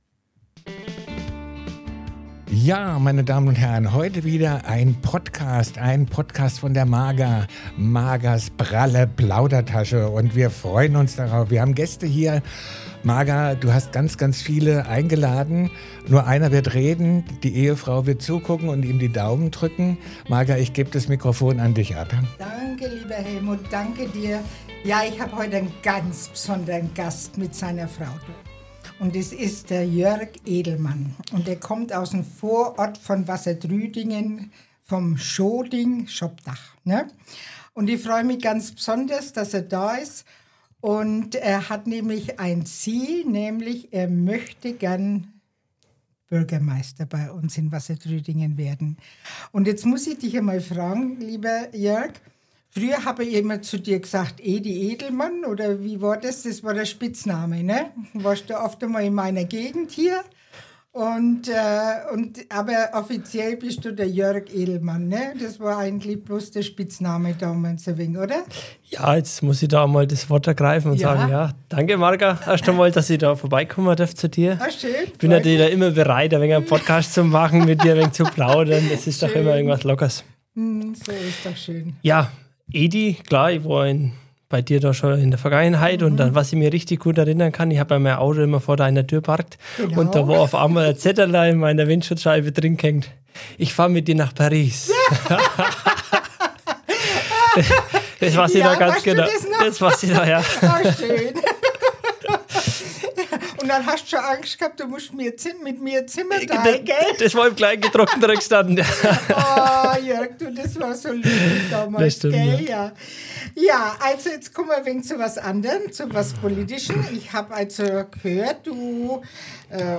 Gemeinsam tauchen wir in die Herausforderungen und Chancen für Wassertrüdingen ein – von belebten Altstädten, Jugendräumen und moderner Gastronomie bis hin zu Wirtschaft, Industrie und dem Einsatz für unsere Senioren. Es wird offen, ehrlich und herzlich diskutiert, wie wir als Gemeinschaft mehr erreichen können.